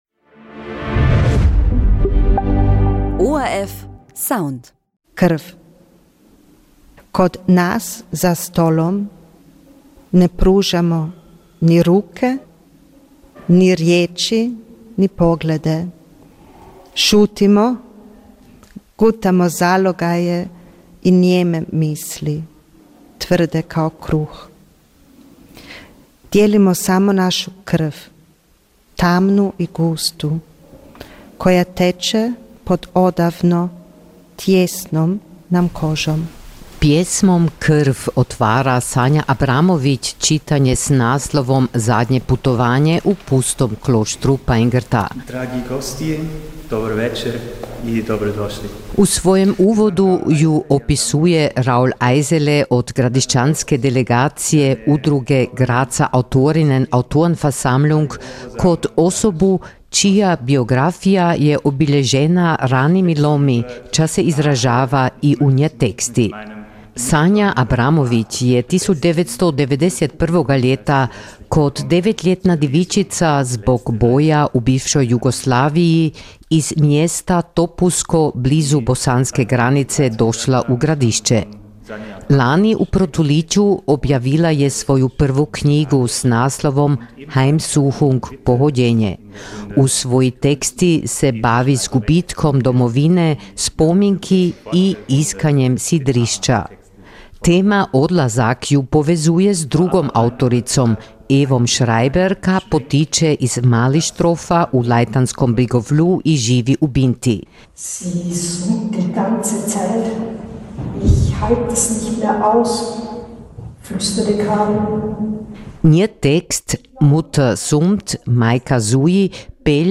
Čitanje s muzikom u Pustom kloštru Pajngrta
„Poslidnje putovanje – približavanje“ je bio naslov literarne priredbe s muzikom u Pustom kloštru Pajngrta.